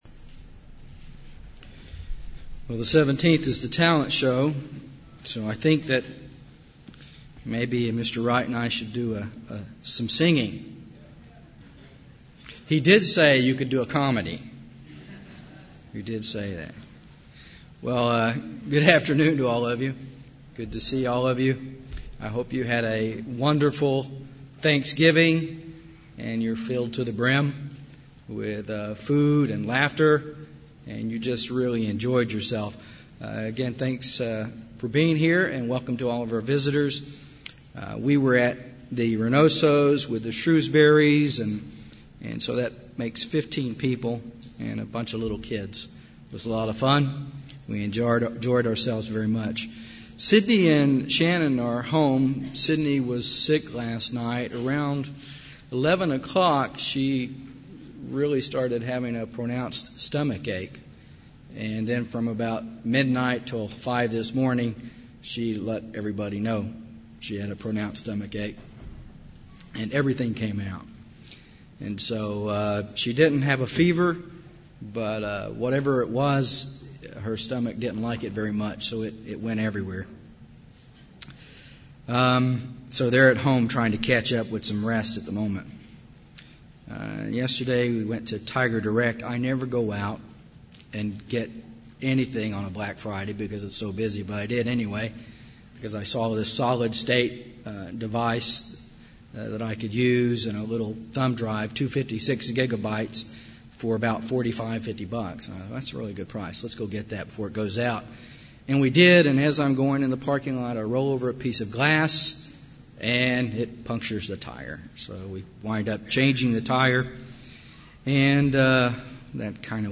UCG Sermon Studying the bible?
Given in Dallas, TX